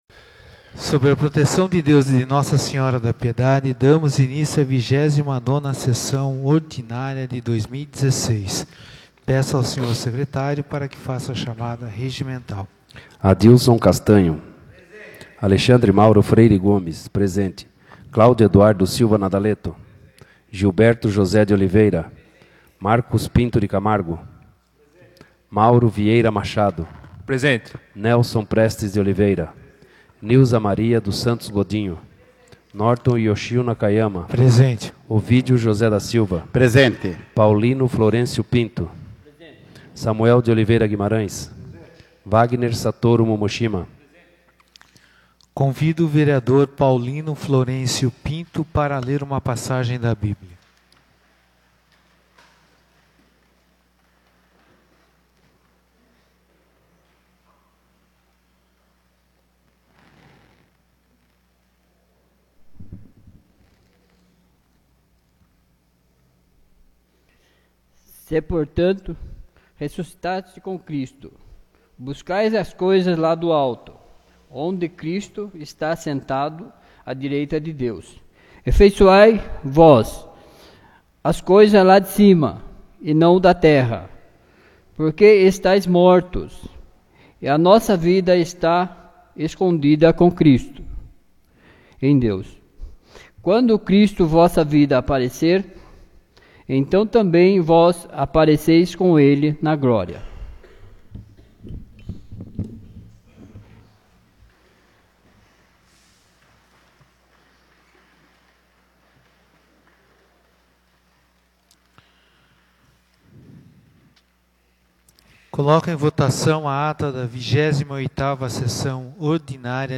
29ª Sessão Ordinária de 2016